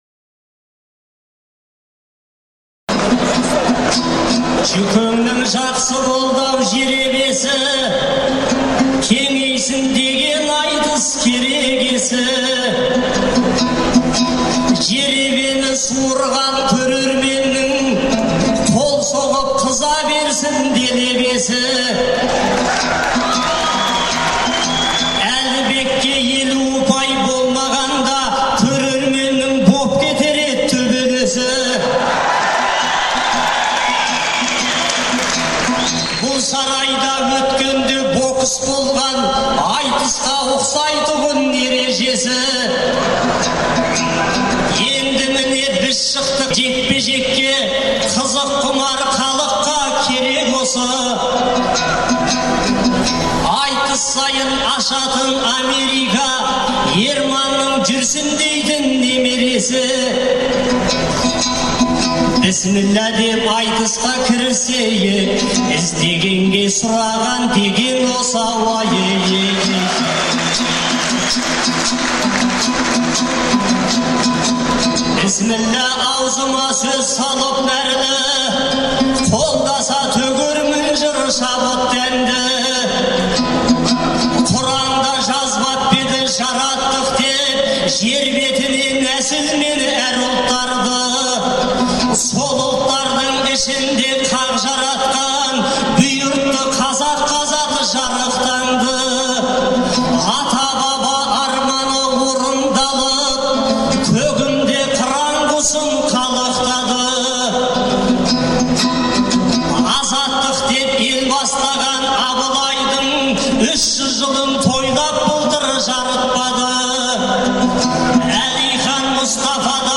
Алматыдағы Балуан Шолақ атындағы спорт сарайында ақпанның 11-і мен 12-сі күні «Қонаевтай ер қайда» деген атпен айтыс өтті.